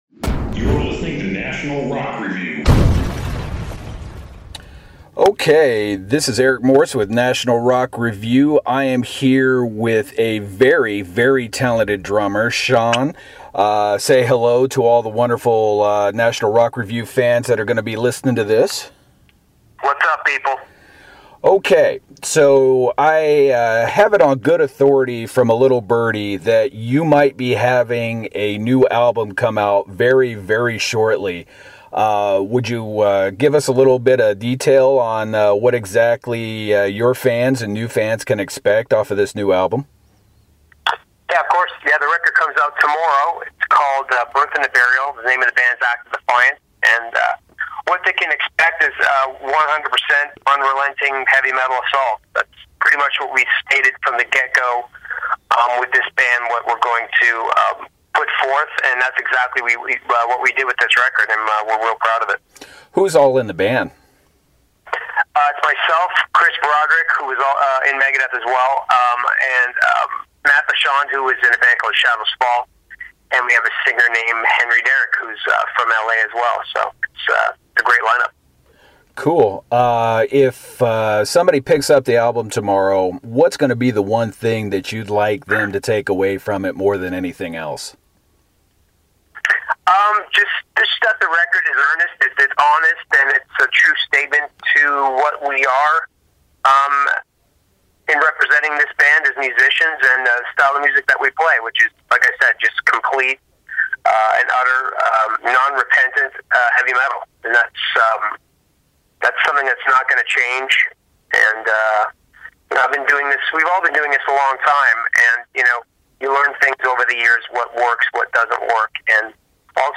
Shawn Drover of Act Of Defiance Interview